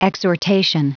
Prononciation du mot exhortation en anglais (fichier audio)
Prononciation du mot : exhortation